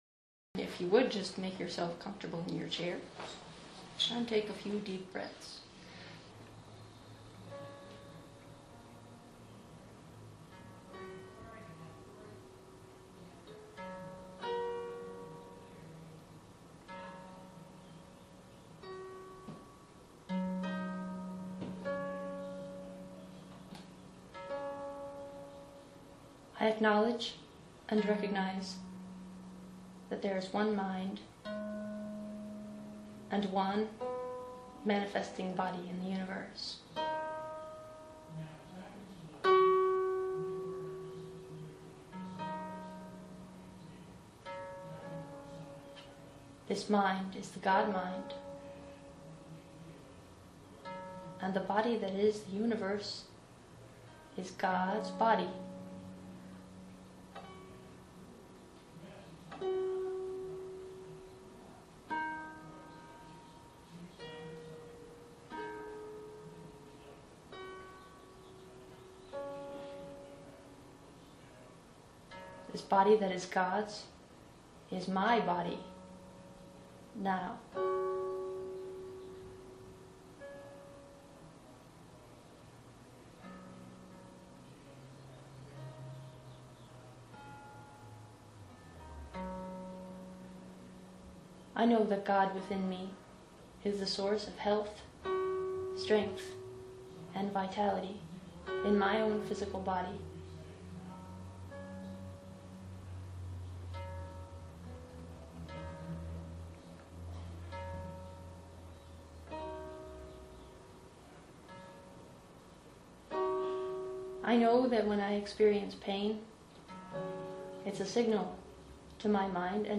This treatment and the following one, are spontaneous, channeled prayers.
Right click and choose “Save target as…” or similar to download this treatment with harp accompaniment.